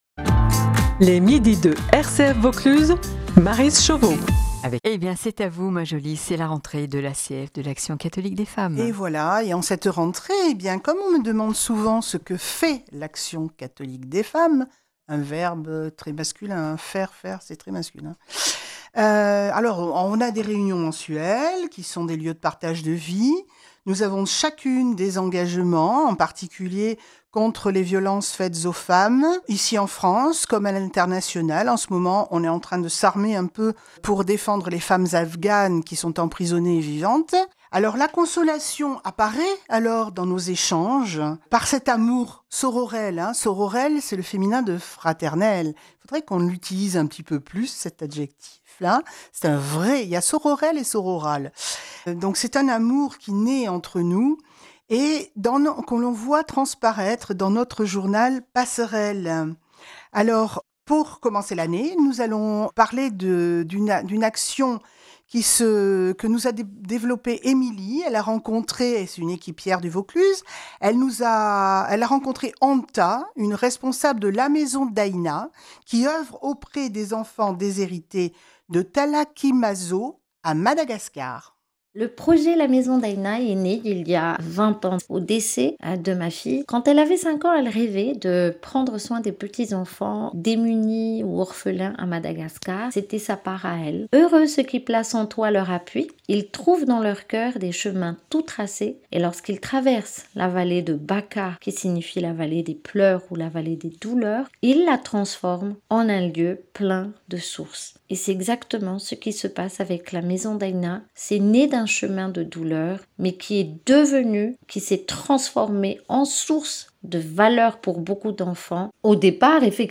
Vous trouverez un extrait d’une émission Rcf en Vaucluse